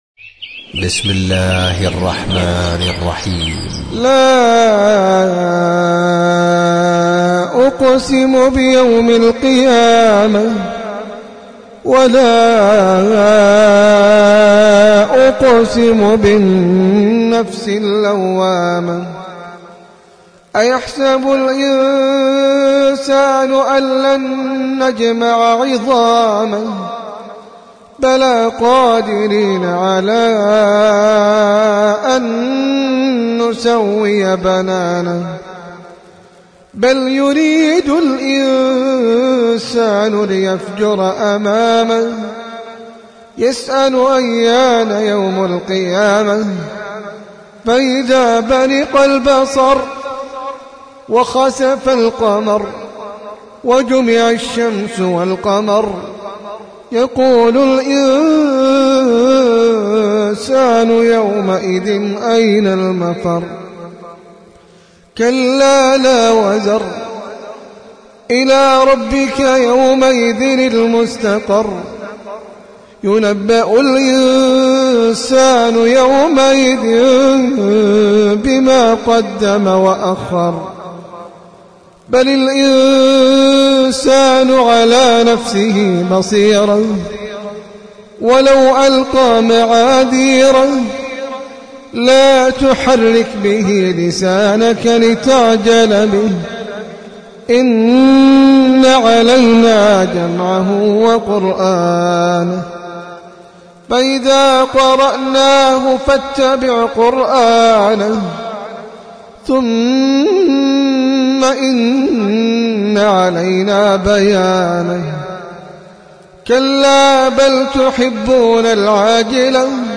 سورة القيامة - المصحف المرتل (برواية حفص عن عاصم)
جودة عالية